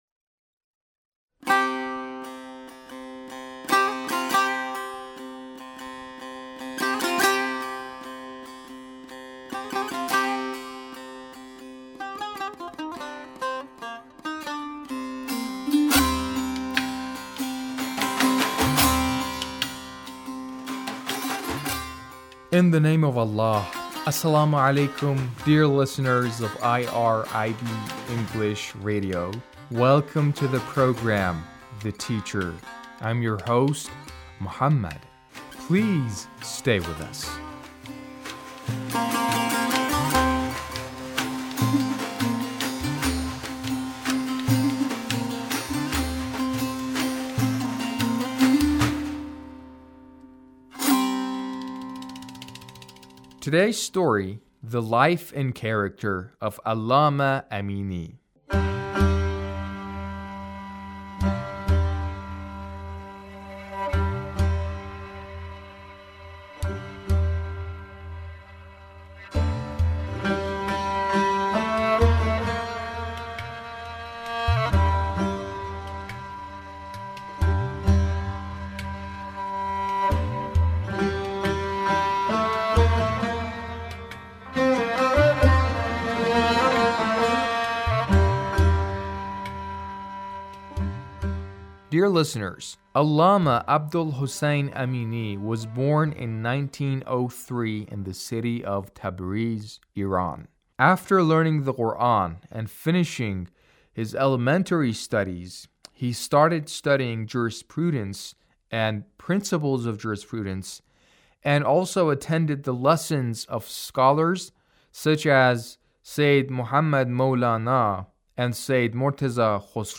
A radio documentary on the life of Allamah Amini